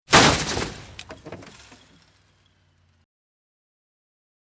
ドシャ（70KB）
マルチメディアカード記録済み効果音12種類